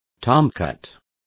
Complete with pronunciation of the translation of tomcats.